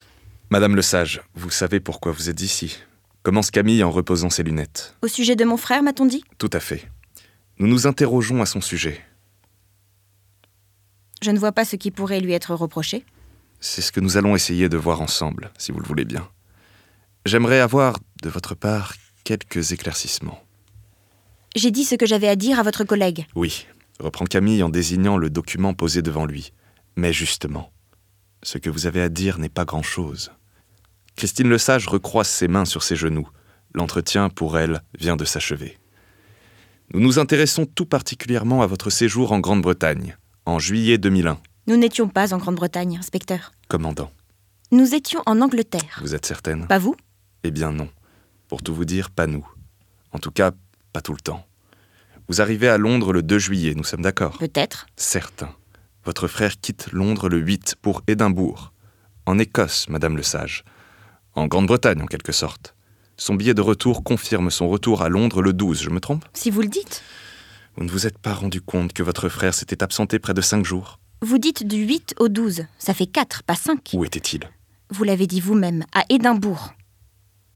texte en duo
20 - 45 ans - Baryton Ténor